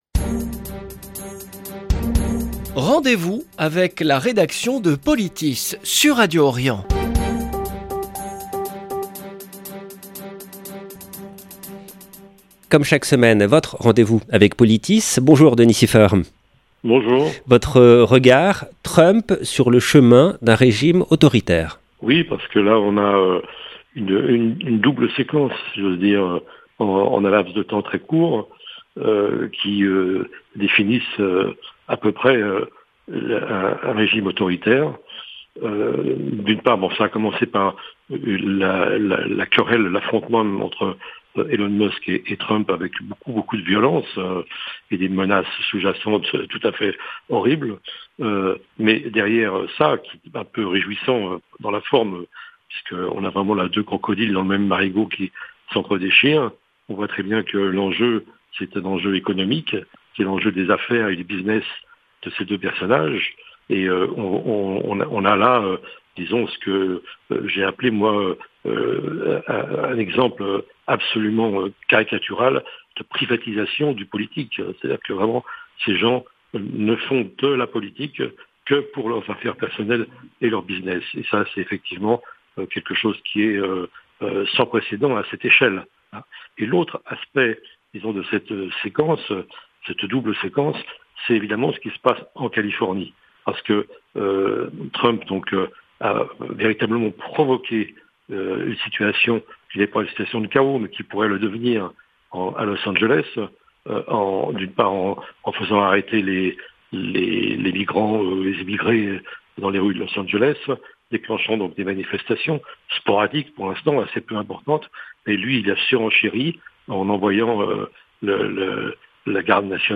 La chronique